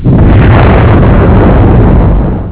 explosion.au